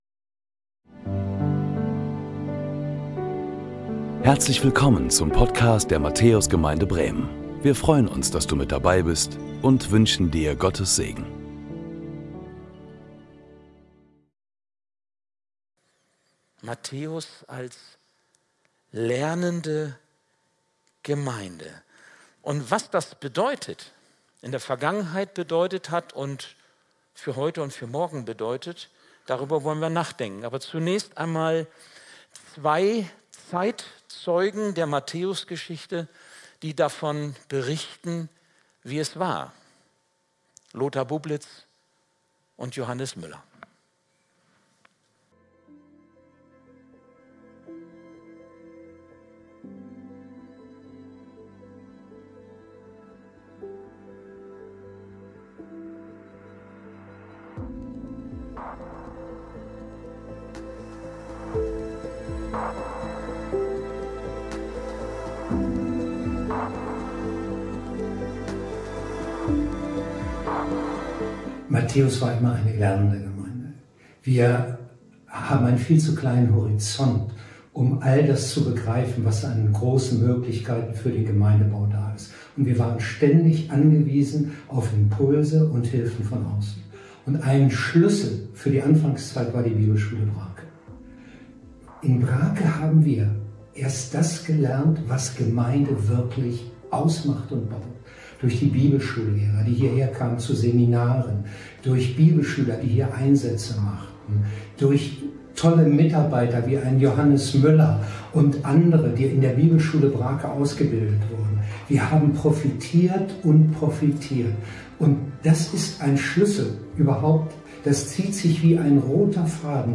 Predigten der Matthäus Gemeinde Bremen Unser Erbe - #7 Lebens langes Lernen